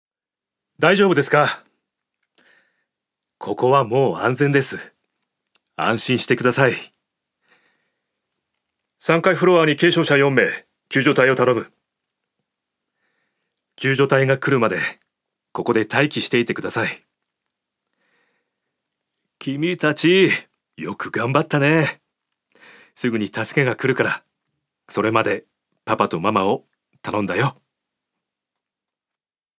所属：男性タレント
ナレーション６